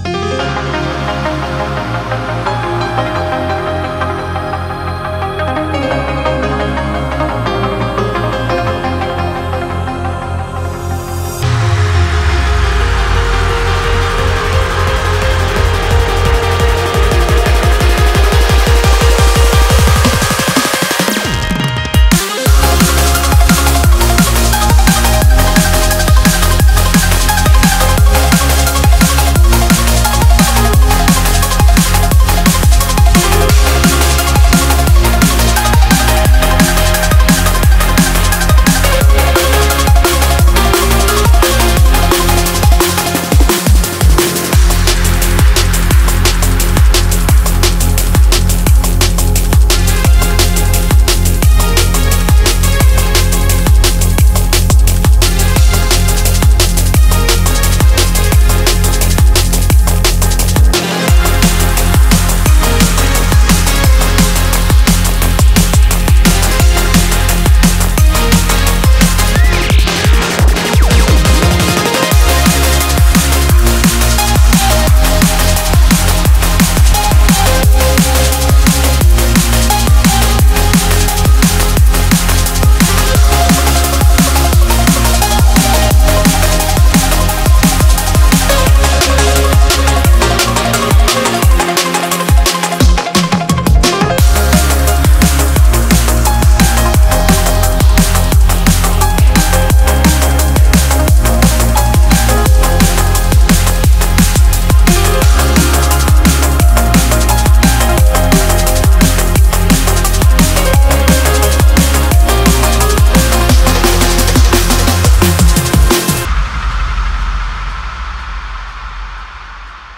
BPM174
Audio QualityPerfect (High Quality)
Commentaires[DRUM & BASS]